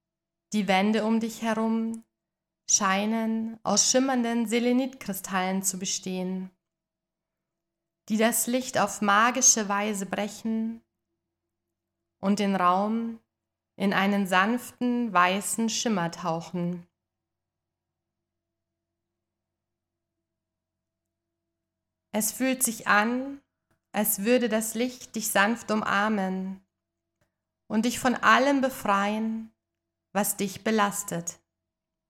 • Format: Geführte Meditationen (Audio-Dateien)